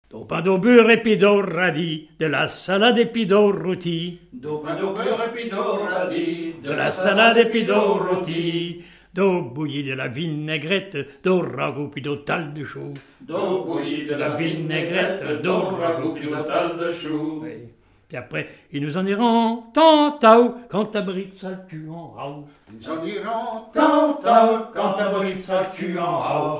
branle
Couplets à danser
Pièce musicale inédite